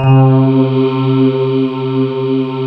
Index of /90_sSampleCDs/USB Soundscan vol.28 - Choir Acoustic & Synth [AKAI] 1CD/Partition D/07-STRATIS